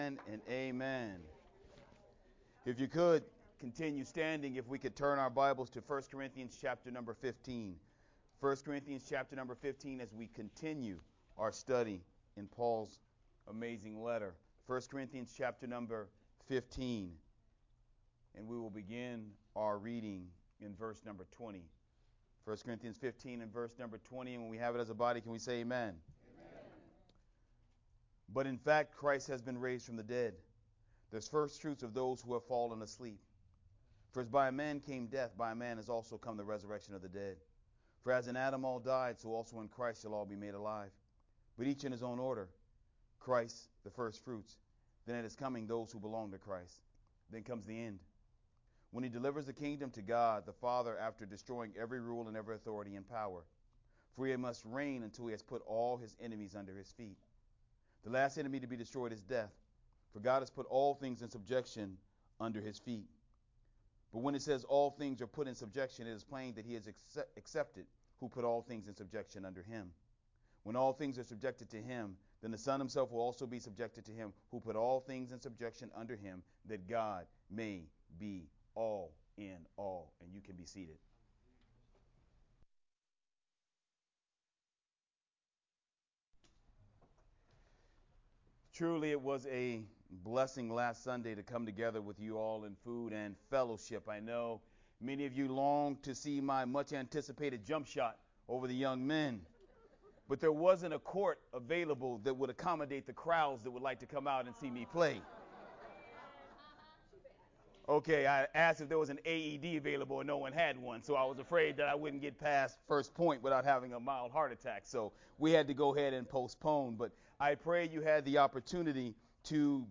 Sermon From 1st Corinthians 15:20-24